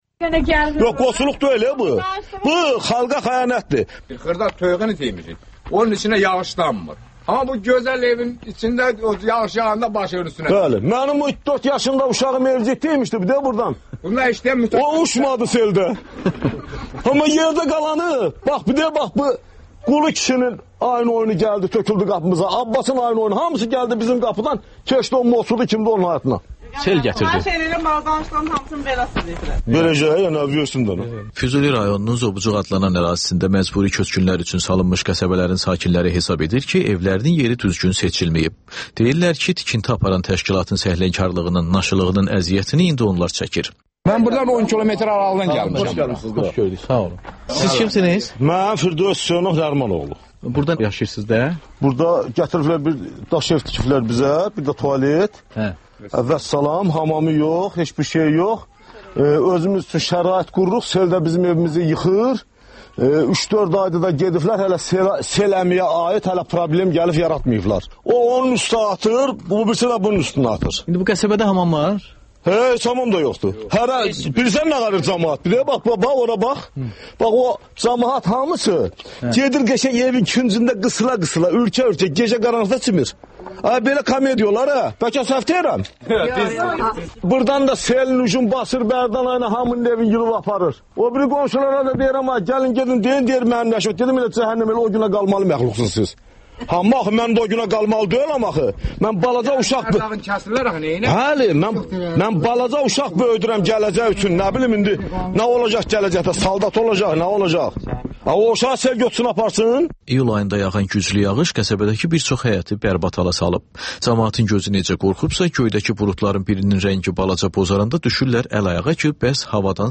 XÜSUSİ REPORTAJ